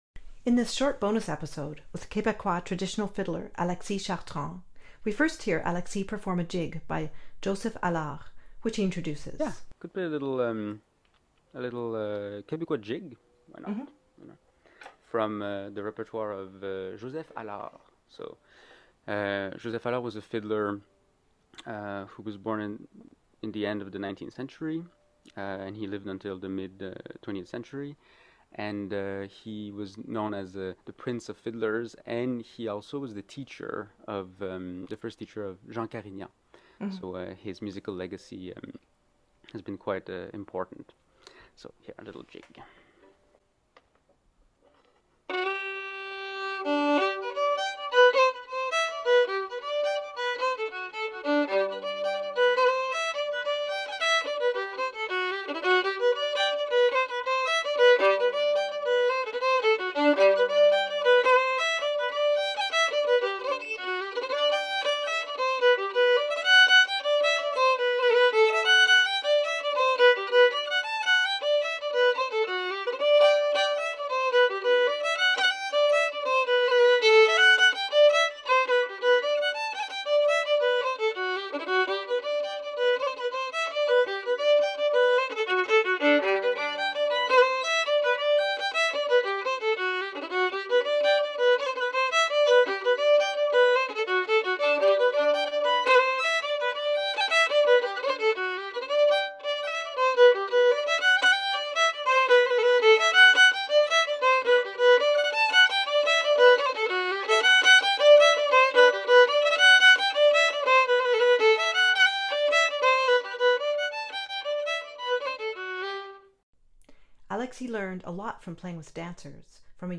Fiddler
Conversations with Musicians